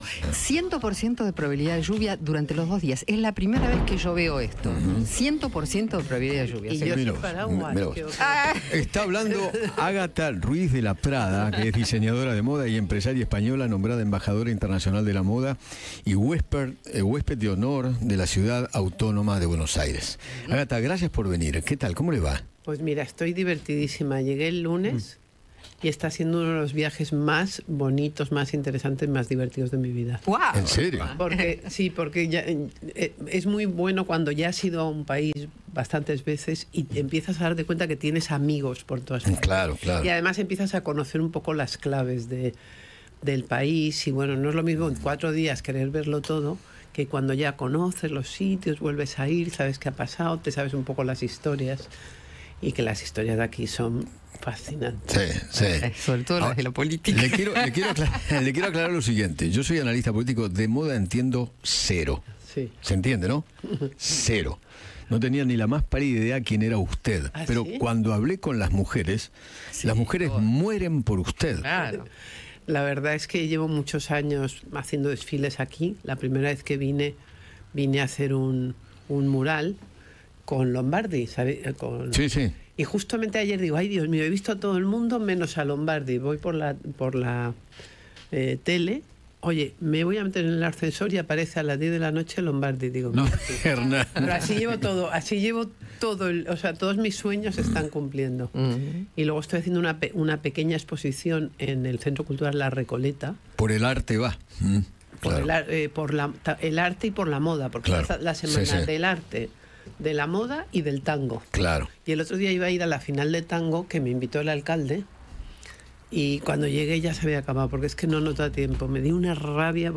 La diseñadora española Agatha Ruiz de la Prada, quien fue reconocida en el Distrito Buenos Aires Fashion & Arts – BAFA, visitó los estudios de Radio Mitre y dialogó con Eduardo Feinmann sobre su encuentro con la ex primera dama.